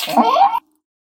jump.ogg.mp3